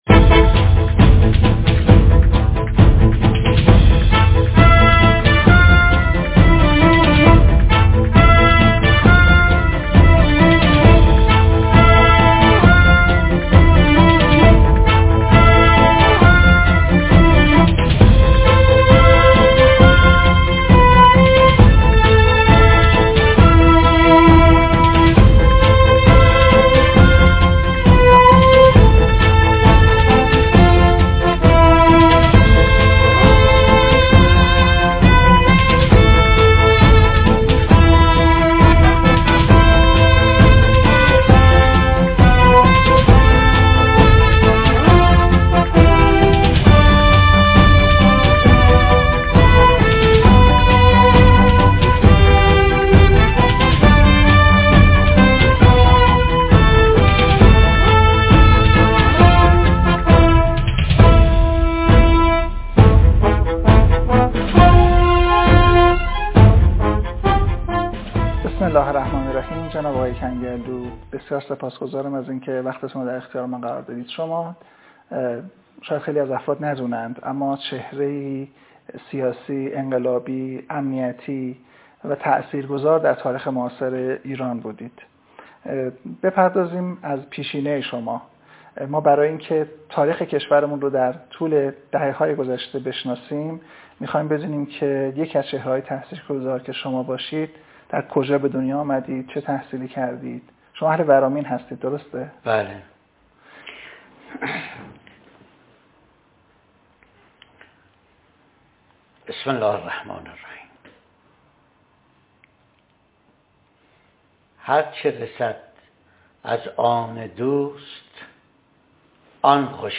موضوع: مصاحبه استثنائی با یک عنصر رده بالای امنیتی مصاحبه حسین دهباشی و محسن کنگرلو نسخه سانسور نشده) صوتی( ویدیویی